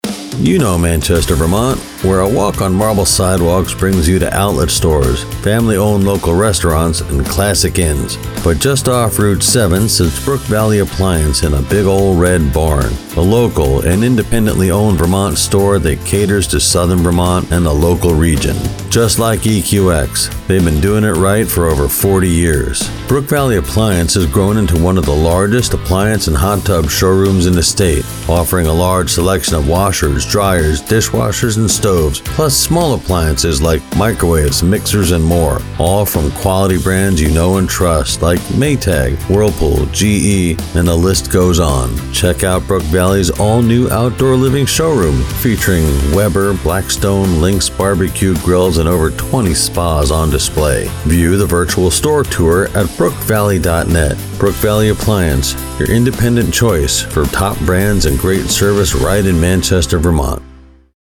Brook Valley Appliance Commercial
Senior